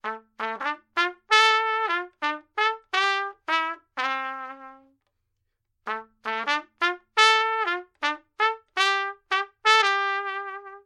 Trompette
Famille : vent/cuivre
Descriptif : c’est l’instrument le plus aigu de sa famille. Elle est formée d’un long tube d’environ 1,50 m. Pour produire le son, il faut faire vibrer ses lèvres sur l’embouchure.